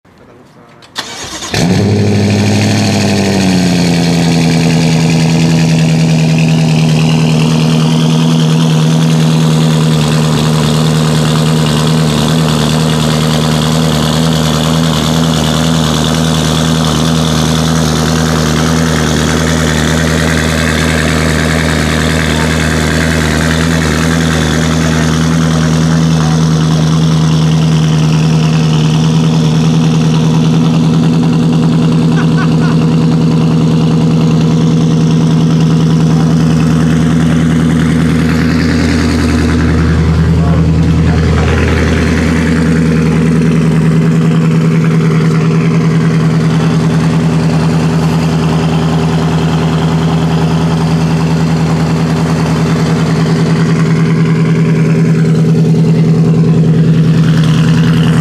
Звуки выхлопа машин
На этой странице собрана коллекция мощных и чистых звуков выхлопа различных автомобилей и мотоциклов.